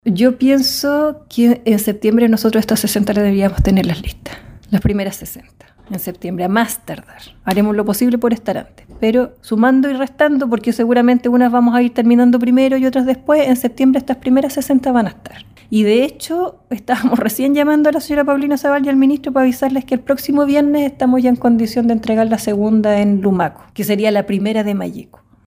En entrevista con Radio Bío Bío, la seremi de Vivienda en La Araucanía, Ximena Sepúlveda, confirmó que hasta la fecha mantienen una casa definitiva entregada en Galvarino.